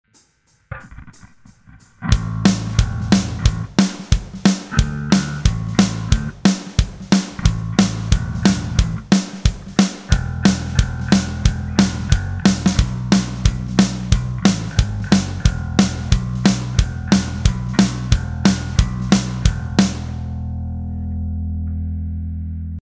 Backing Tracks